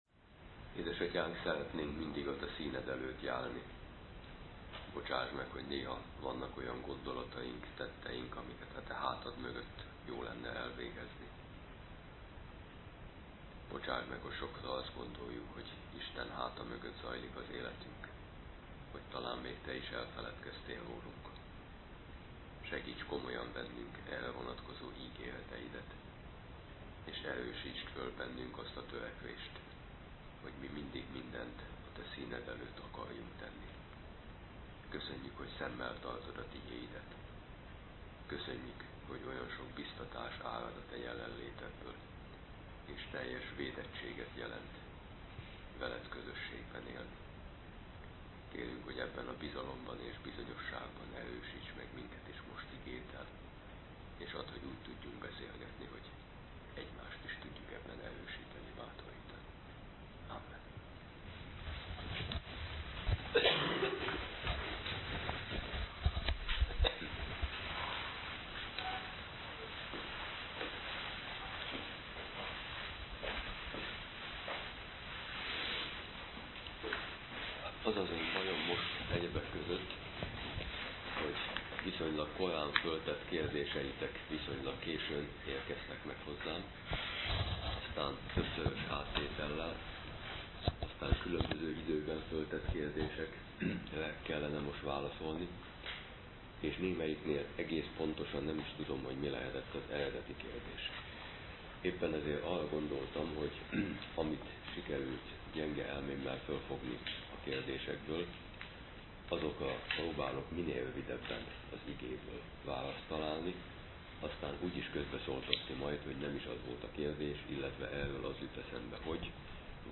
Cselekedeteink és üdvbizonyosságunk (kérdések órája)